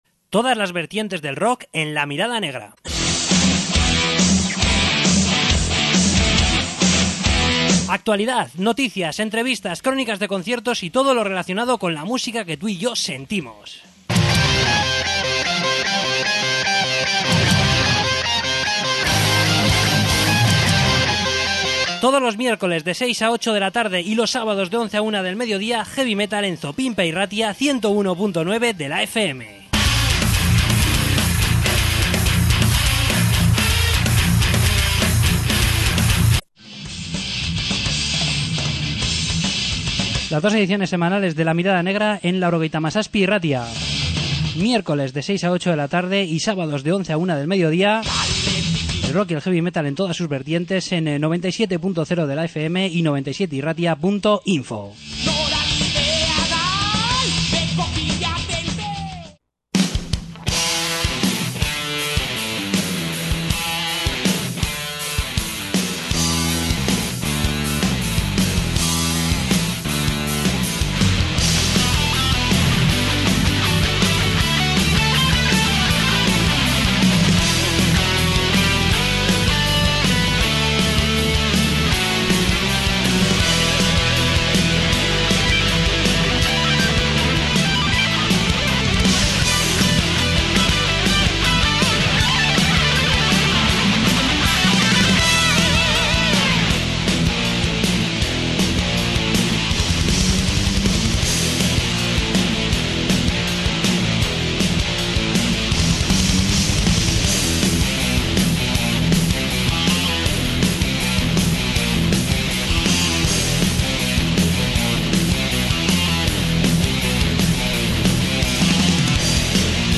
Entrevista con Cardiac
Entrevista con Lotura